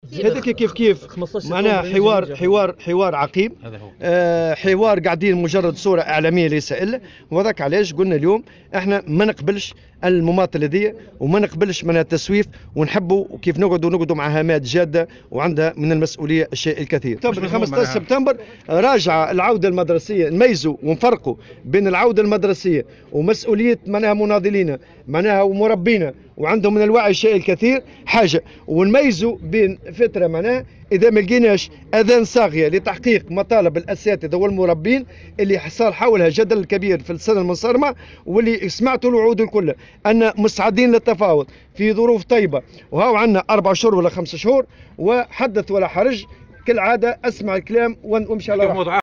وقال في تصريح لمراسل "الجوهرة اف أم" على هامش اجتماع نقابي بالمنستير، إن النقابيين لا يقبلون "المماطلة والتسويف" وطالب بالجلوس "مع هامات جادة ومسؤولة" عند التفاوض بحسب تعبيره، مؤكدا استعداده للمفاوضات رغم أنها ظلّت تراوح مكانها منذ 5 أشهر تقريبا.